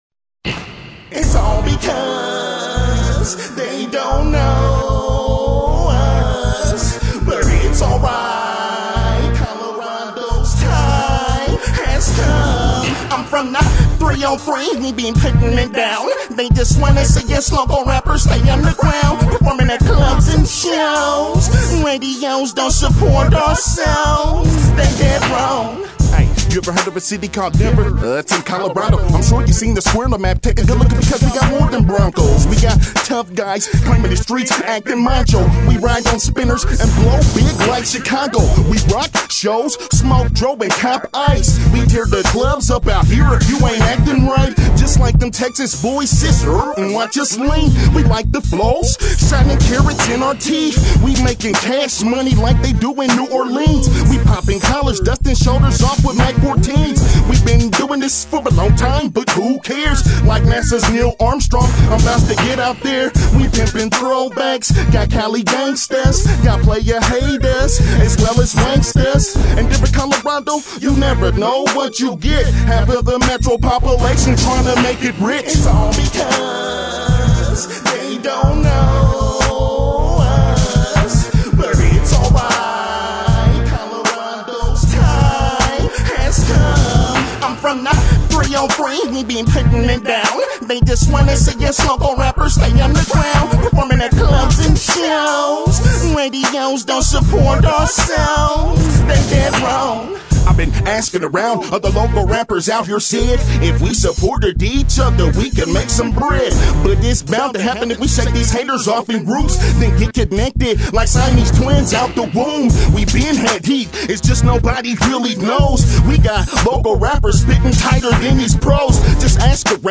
Hip-hop
Rhythm & Blues